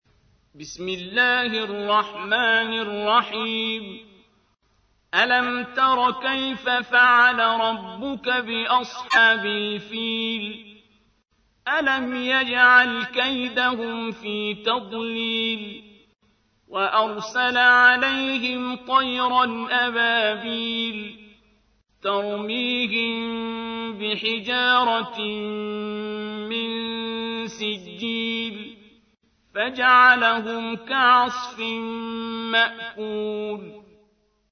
تحميل : 105. سورة الفيل / القارئ عبد الباسط عبد الصمد / القرآن الكريم / موقع يا حسين